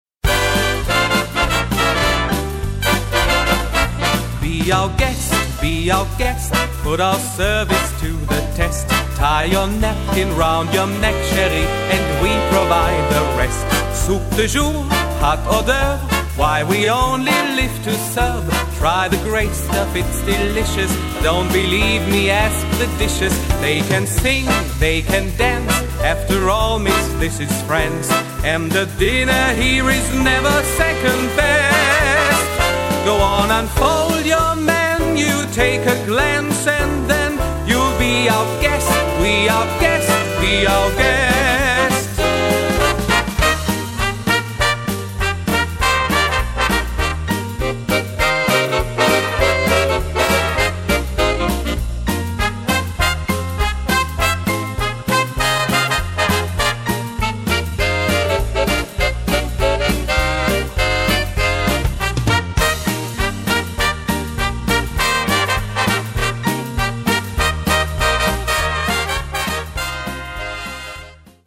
QUICK STEP